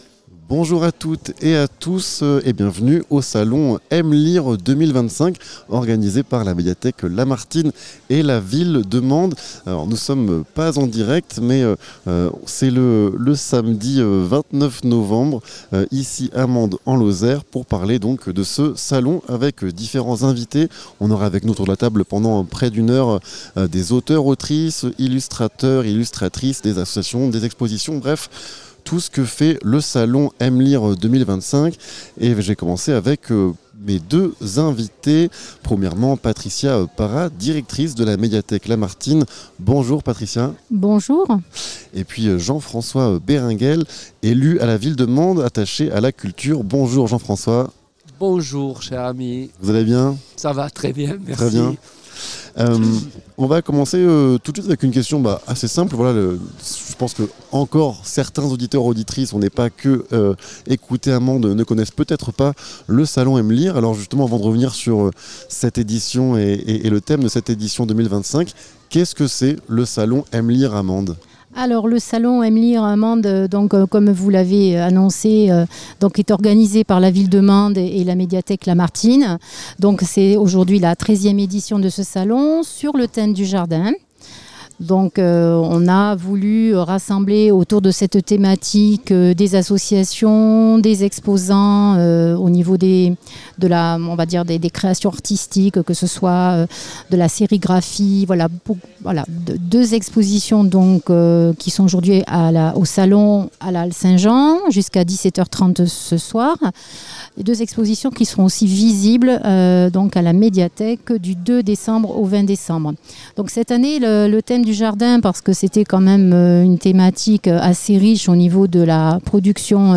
Émission en direct du salon M’Lire 2025 | 48 FM
En direct de la Halle Saint-Jean à Mende.